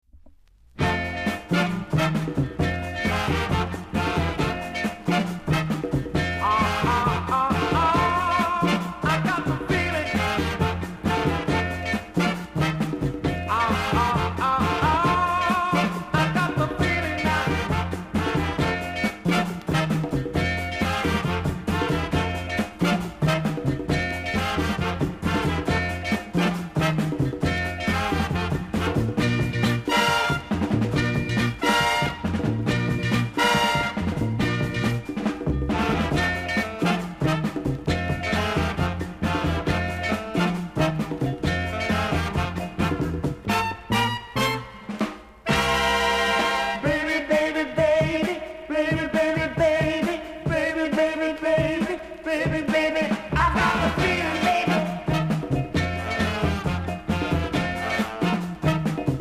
※多少小さなノイズはありますが概ね良好です。
NICE ROCKSTEADY、CALYPSO LP!!